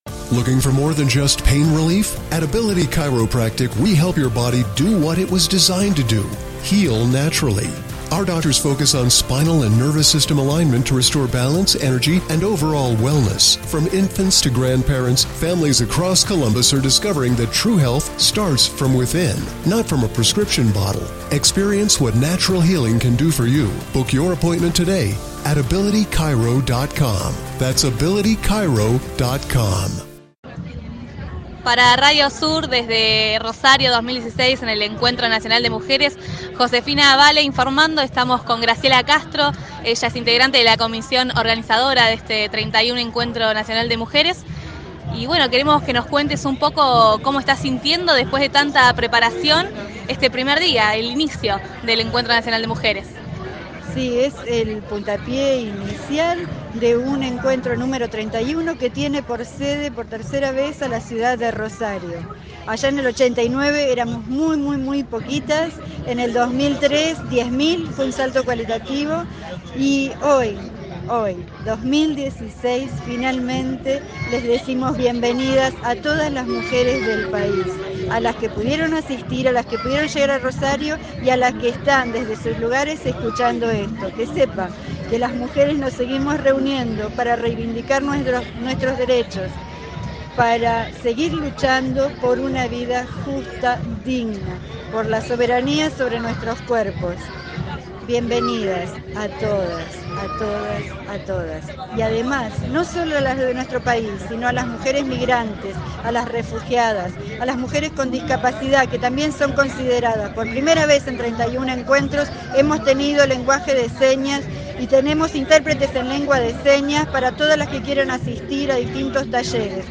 Testimonio
Cobertura especial del 31 Encuentro Nacional de Mujeres realizado durante los días 8, 9 y 10 de octubre en Rosario, Santa Fe.